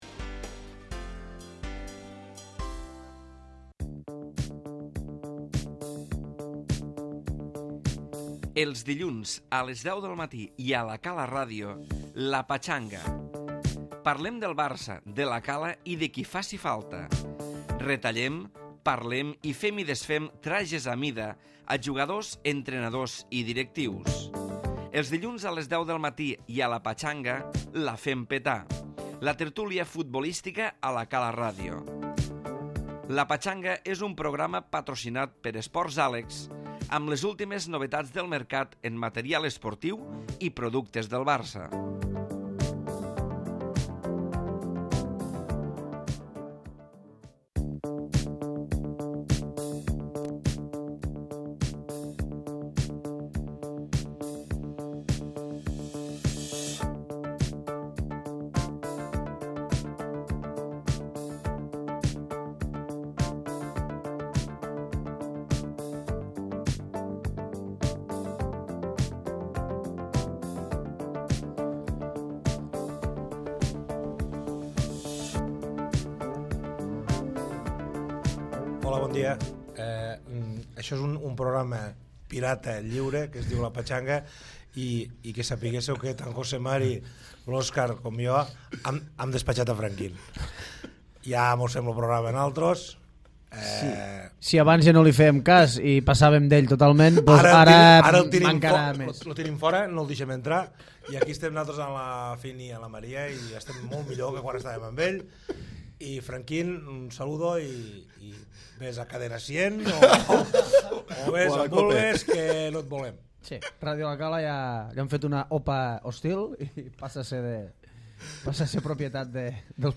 Tertulia futbolística de la Cala Ràdio